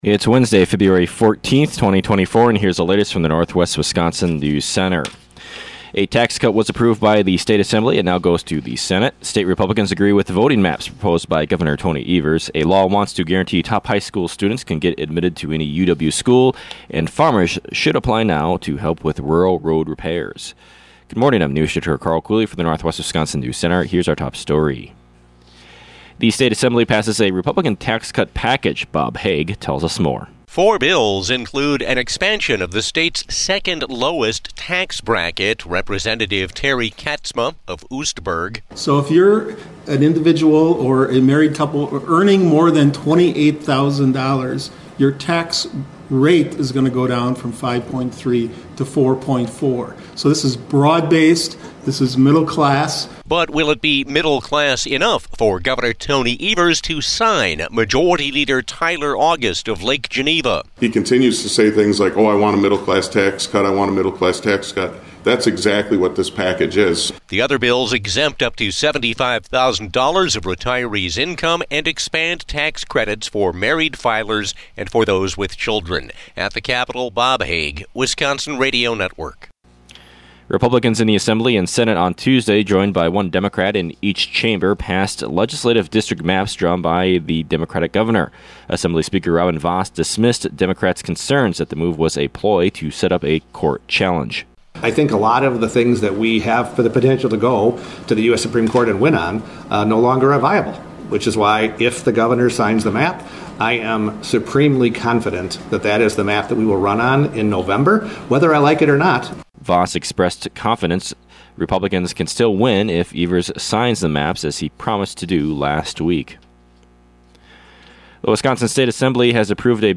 AM NEWSCAST – Wednesday, Feb. 14, 2024 | Northwest Builders, Inc.